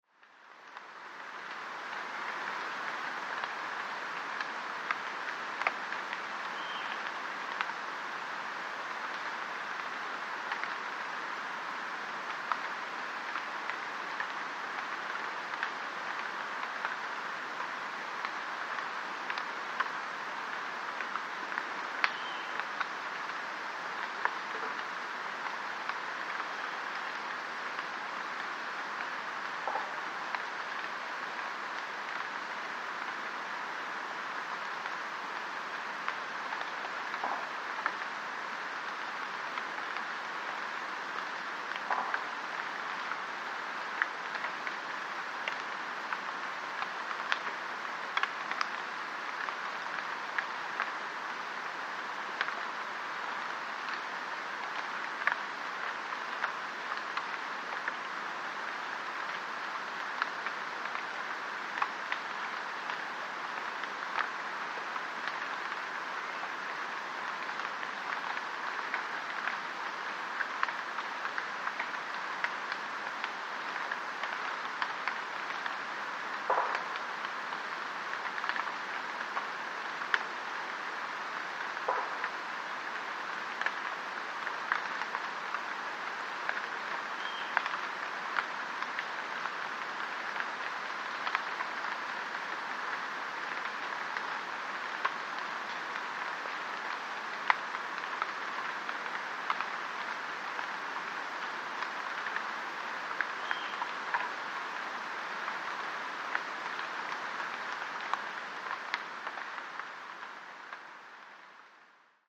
Dry leaves falling at Tikal
Dry leaves falling at Tikal National Park. Stereo 48kHz 24Bit.